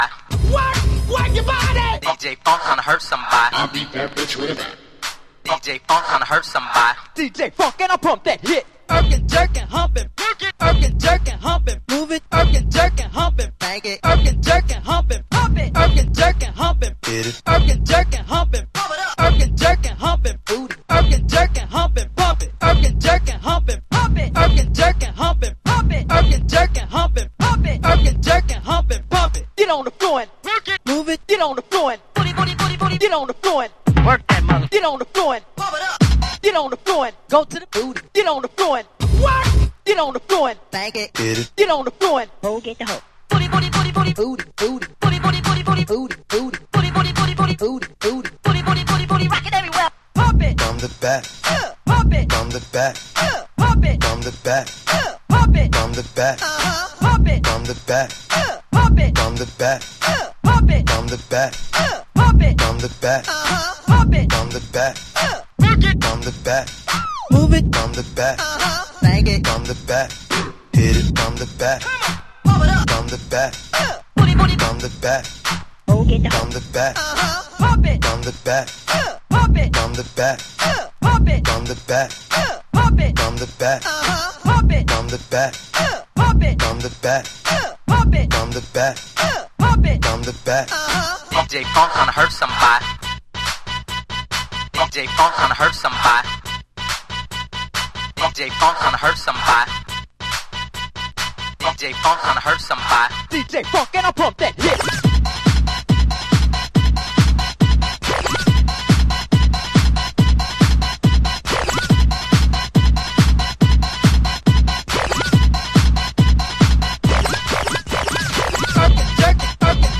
兎にも角にもバウンスバウンス。